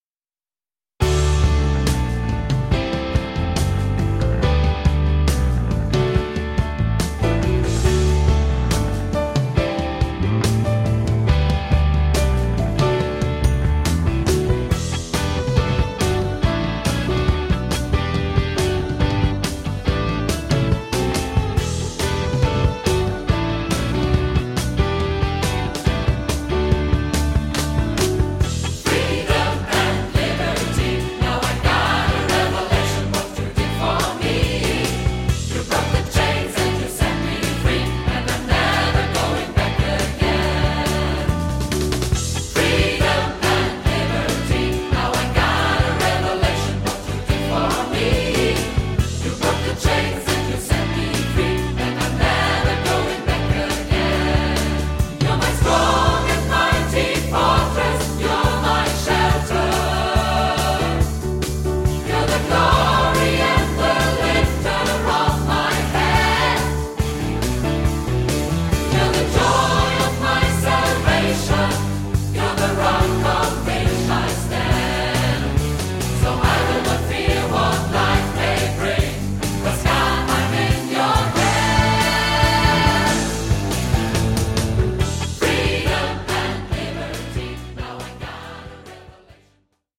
• Stil/Genre: modern
• Sachgebiet: Praise & Worship